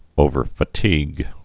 (ōvər-fə-tēg)